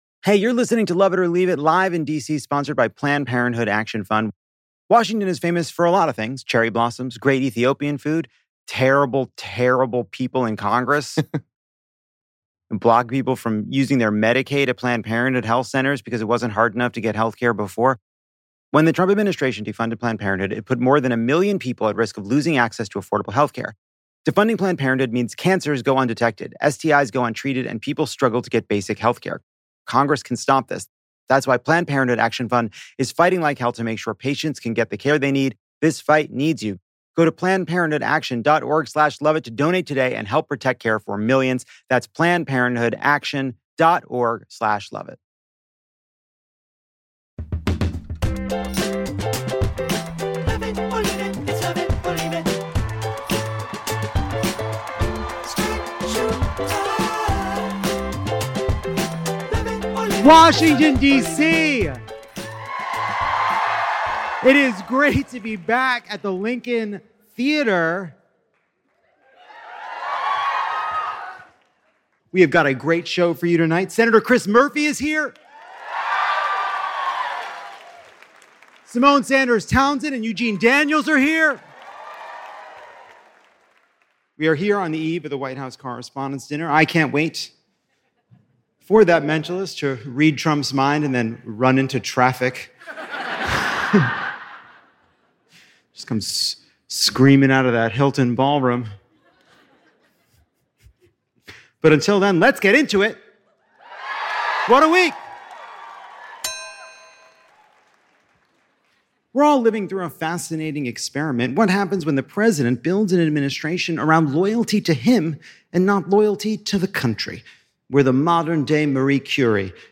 Lovett is joined by Senator Chris Murphy to talk winning the midterms in the face of AI slop and AI SuperPACs. Then MSNow's Symone Sanders Townsend and Eugene Daniels debate how journalists can stand up to Trump when they share a ballroom this weekend, and we answer a few tough questions facing Democrats. Finally, the rant wheel spins and a sold out crowd at the Lincoln Theatre shares a few second thoughts.